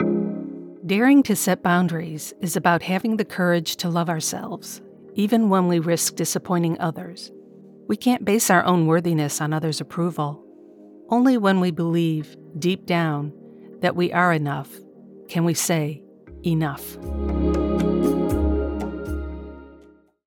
Professional Female Voice Over Talent
Meditation Sample
Let’s work together to bring your words to life with my conversational, authoritative and articulate voice.